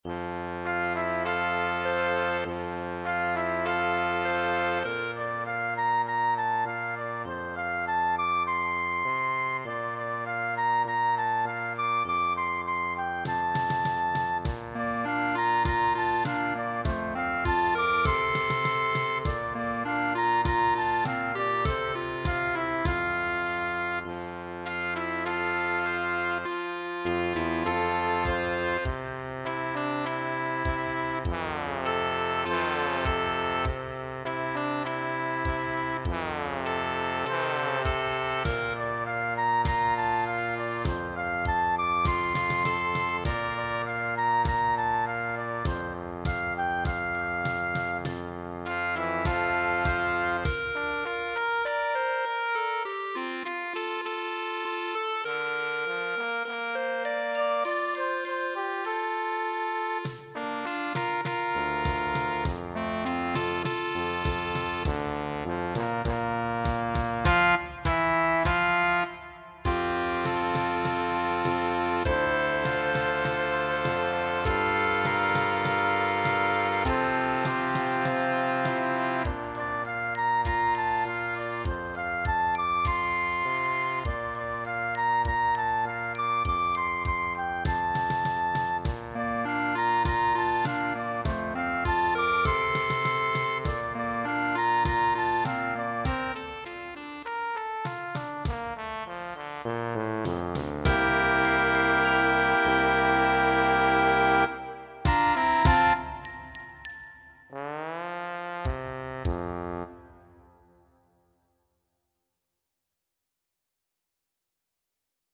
I decided a Junior High Band piece would be just the way to get my feet wet. Here’s a virtual band coming right to you by way of your computer.